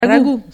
prononciation Ragoût ↘ explication Moi je suis de Bologne, et donc chez nous, ce que les Français appellent “bolognaise”, ça s’appelle “ragoût”.